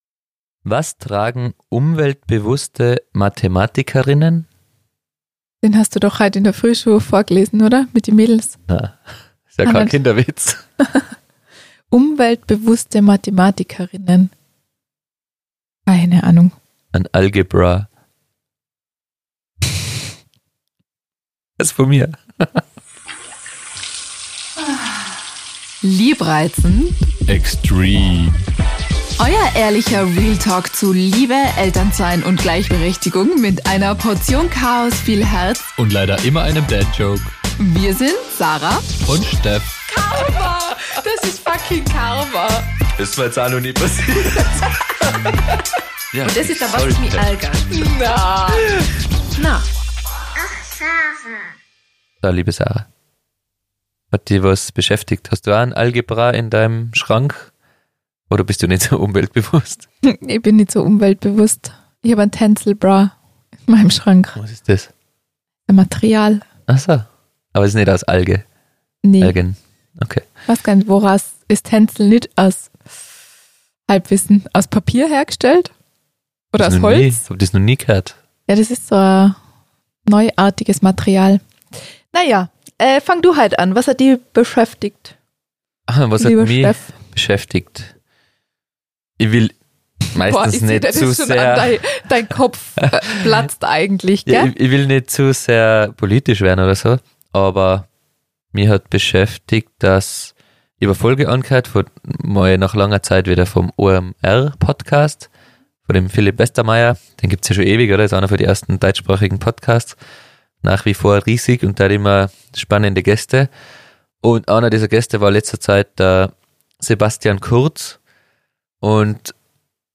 Was steckt wirklich dahinter, wenn Paare für sich für dieses Beziehungsmodell entscheiden, wir erzählen offen und ehrlich wie wir darüber denken und ob das Modell für uns in Frage kommen könnte. Wir sprechen aber auch darüber wie ein Wertekompass ausgerichtet sein sollte und ab wann man Menschen unter Umständen keine Bühne mehr geben sollte. Außerdem wird es kurzzeitig ziemlich emotional beim Thema 'Hilfe holen und annehmen'.